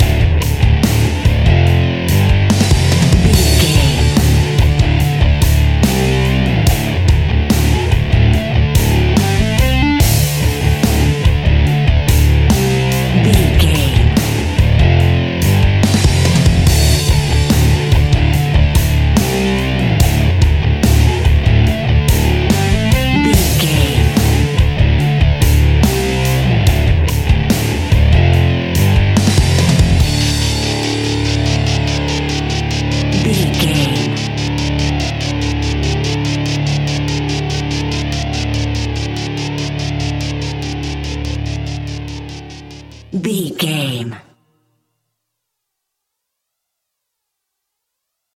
Epic / Action
Aeolian/Minor
Slow
hard rock
heavy metal
distortion
rock guitars
Rock Bass
heavy drums
distorted guitars
hammond organ